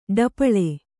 ♪ ḍapaḷe